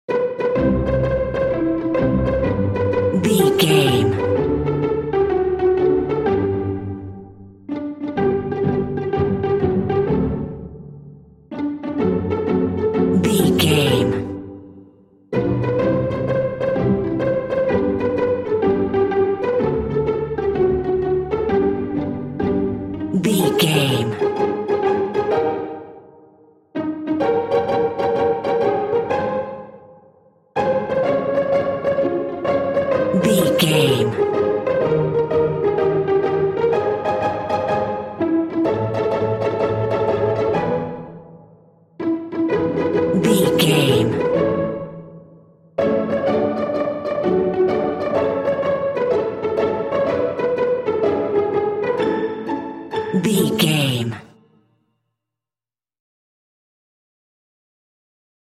Uplifting
Ionian/Major
kids music